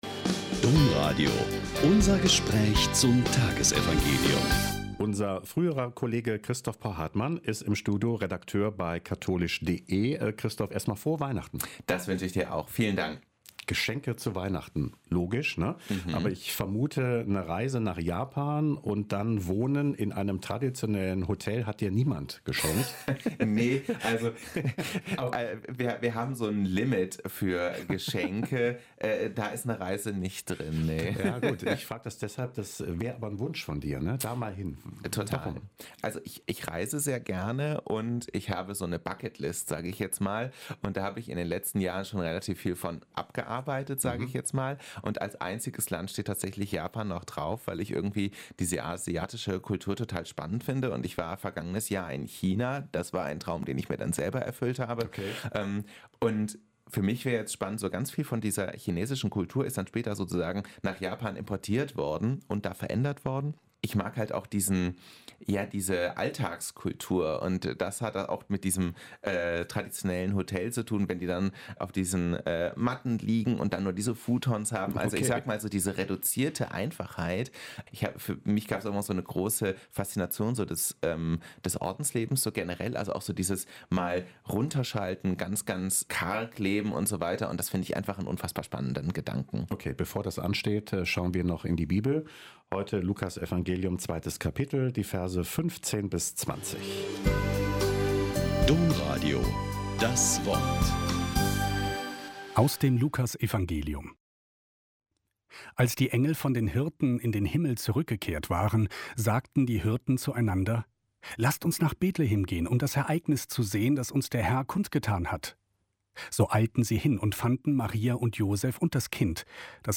Lk 2,15-20 - Gespräch